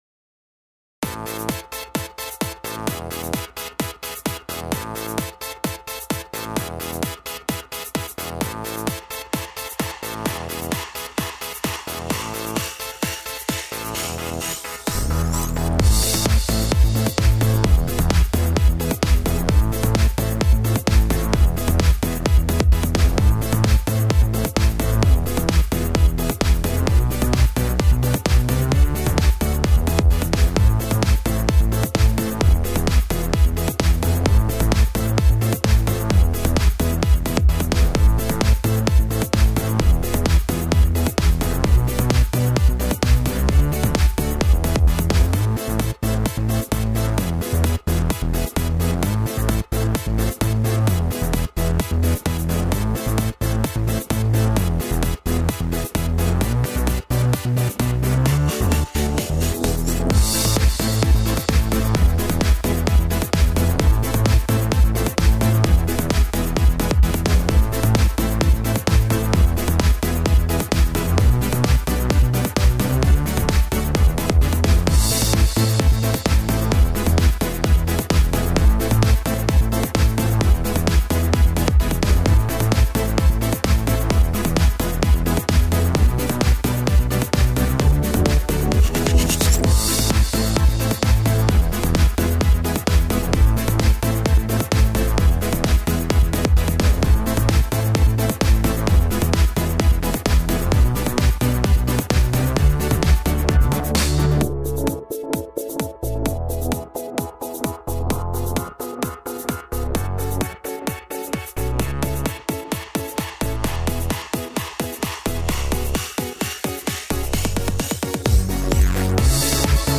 Housey. Work in progress.